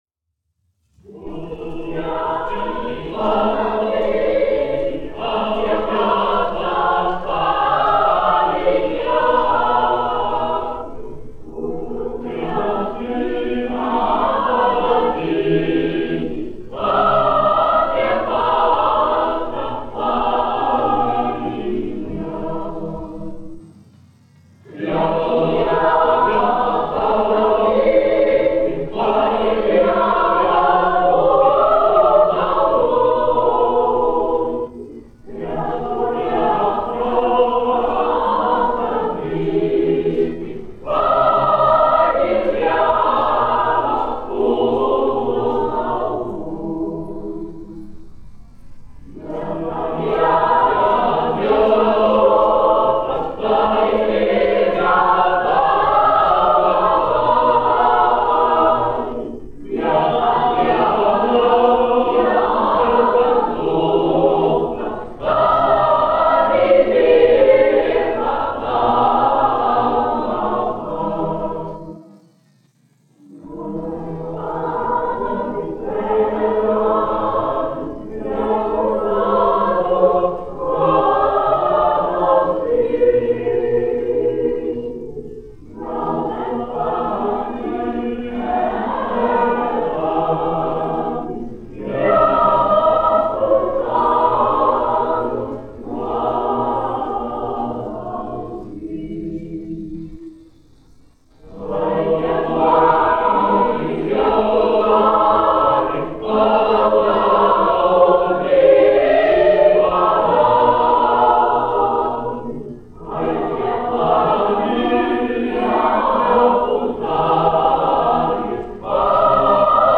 Kur tu skriesi, vanadziņi : tautas dziesma
Latvijas Nacionālā opera. Koris, izpildītājs
1 skpl. : analogs, 78 apgr/min, mono ; 25 cm
Latviešu tautasdziesmas
Kori (jauktie)
Skaņuplate